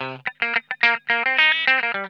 TWANGY 2.wav